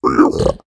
sounds / mob / piglin / idle3.ogg